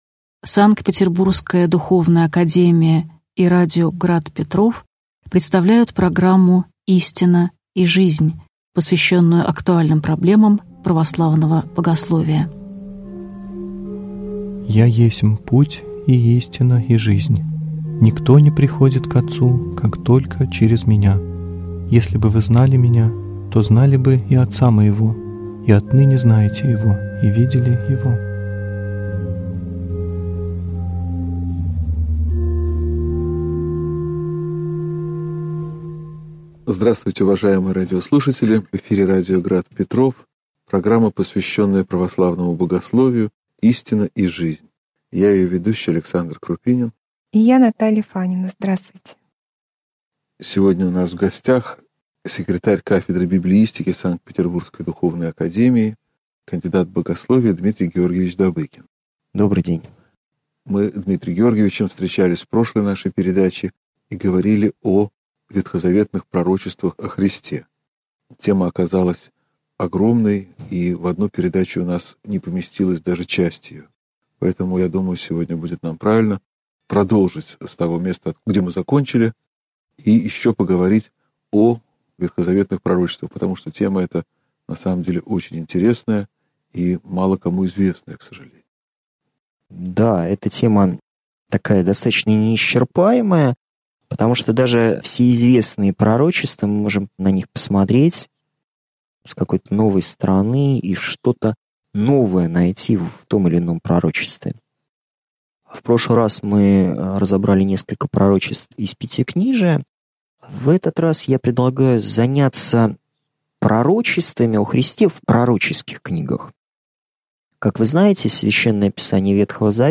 Aудиокнига Единство Ветхого и Нового Заветов (часть 2)